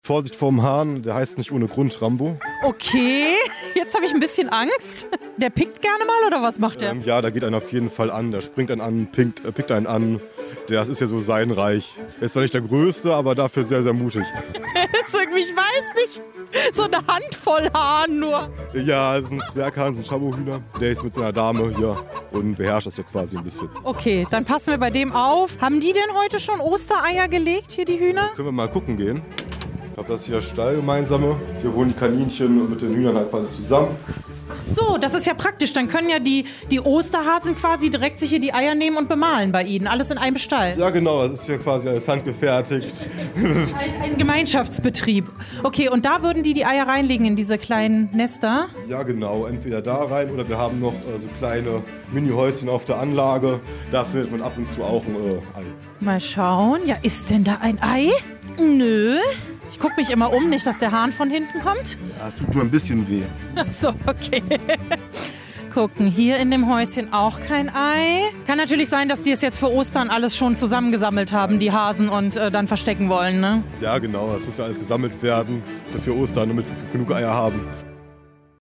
repo_fauna_2_eiersuche.mp3